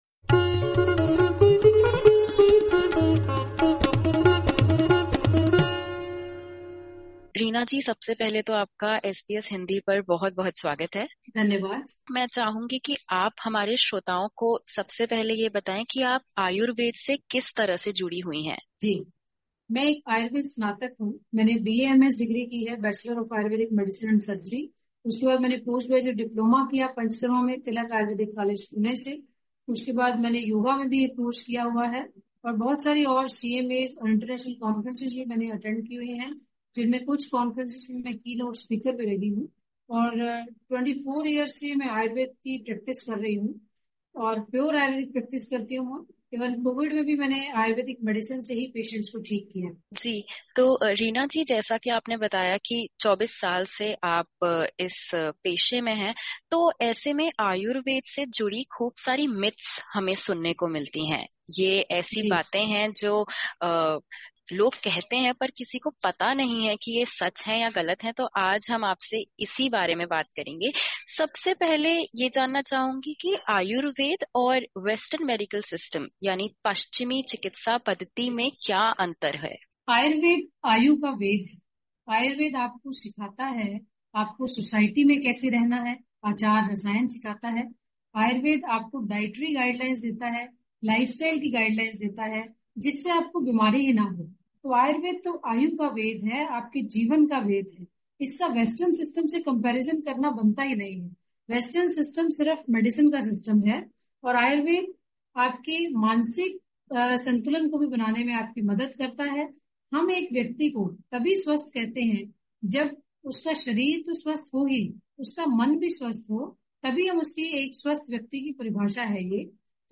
Disclaimer: The information given in this interview is of general nature.